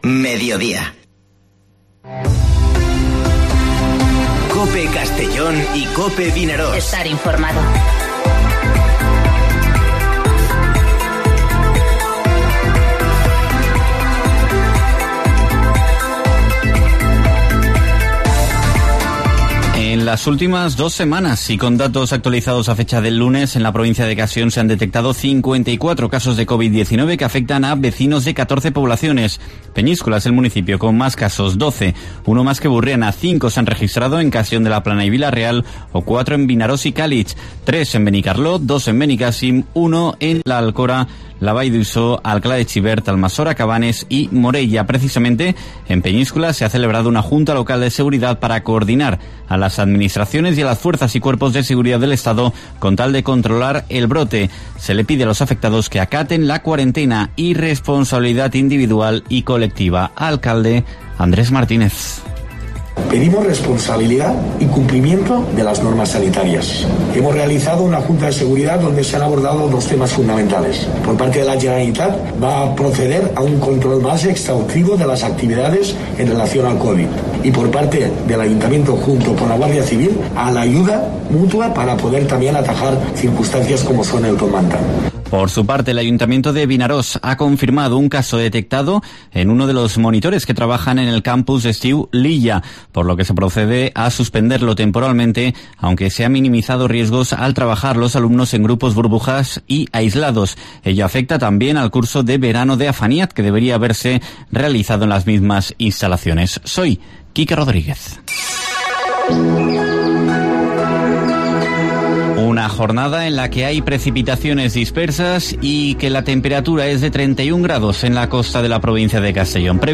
Informativo Mediodía COPE en la provincia de Castellón (22/07/2020)